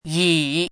chinese-voice - 汉字语音库
yi3.mp3